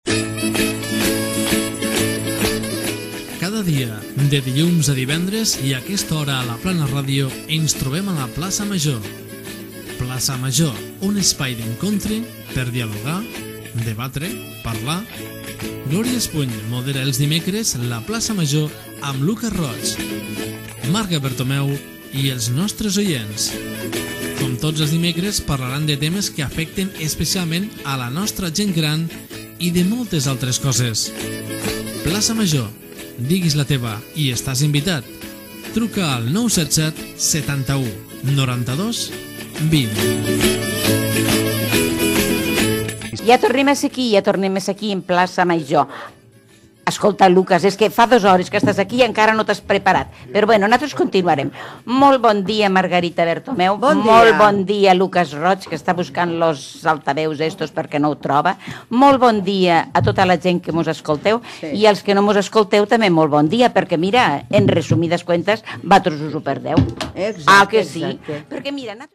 Careta del programa, presentació, telèfon de participació i espai dedicat a la gent gran
Entreteniment
FM